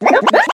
One of Koopa Troopa's voice clips in Mario Kart Wii